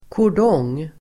Ladda ner uttalet
kordong substantiv, cordon Uttal: [kår_d'ång:] Böjningar: kordongen, kordonger Definition: kedja för avspärrning Sammansättningar: poliskordong (police cordon) cordon substantiv, kordong Förklaring: kedja för avspärrning